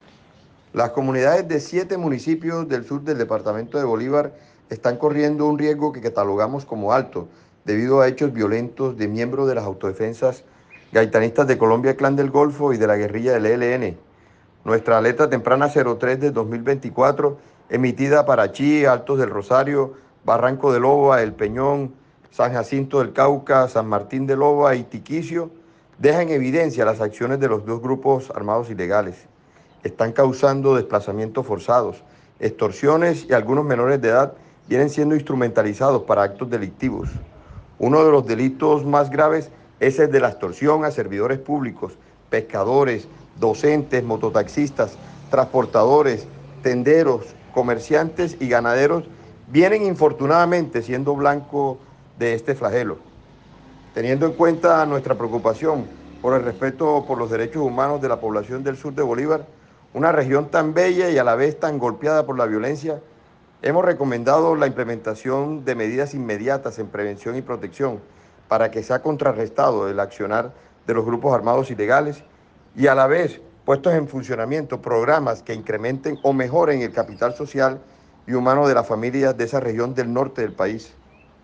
Pronunciamiento del Defensor del Pueblo